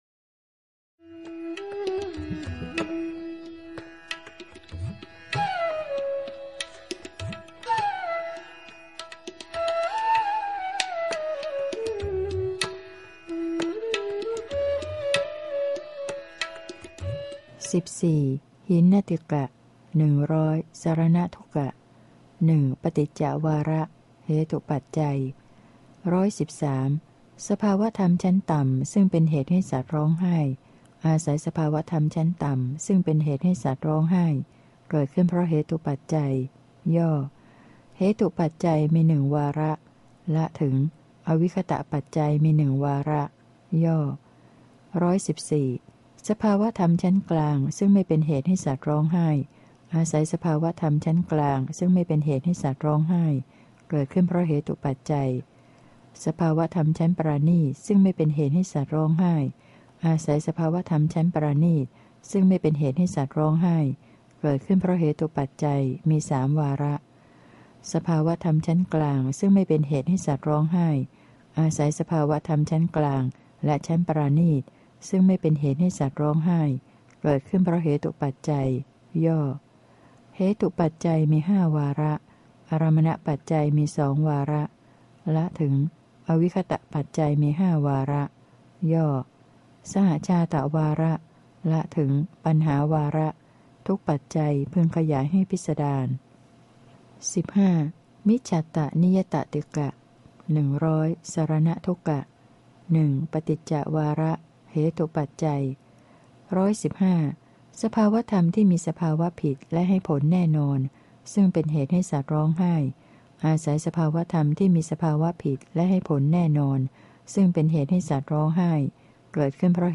พระไตรปิฎก ภาคเสียงอ่าน ฉบับมหาจุฬาลงกรณราชวิทยาลัย - เล่มที่ ๔๔ พระอภิธรรมปิฏก